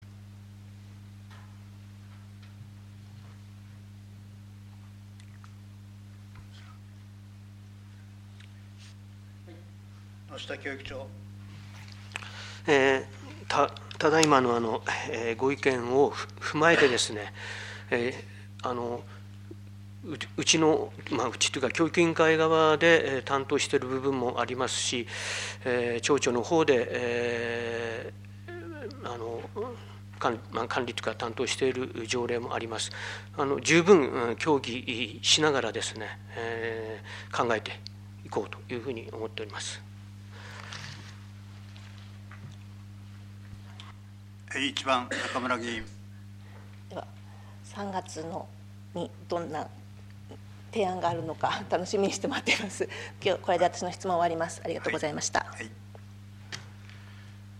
一般質問 平成２９年 | 一般質問 | 陸別町議会 | 日本一寒い町 北海道 陸別町
１２月定例会（１２月１２日～１３日）